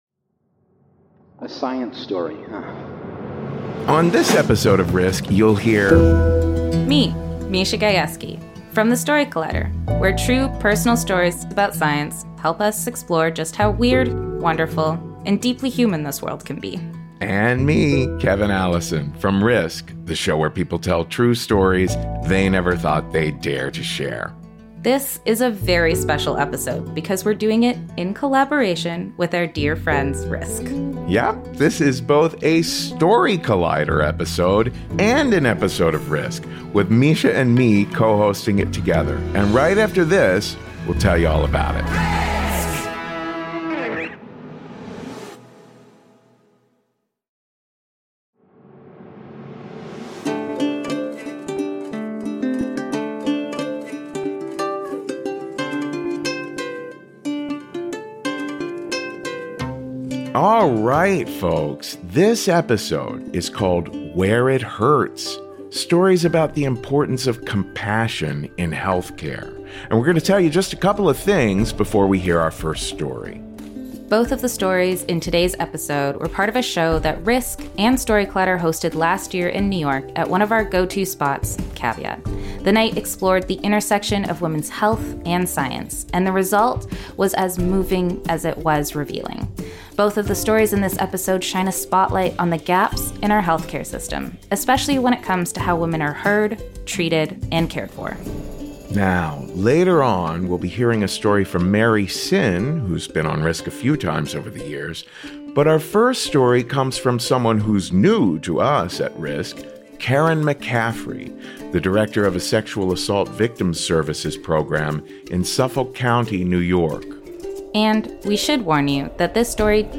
🎤Live Story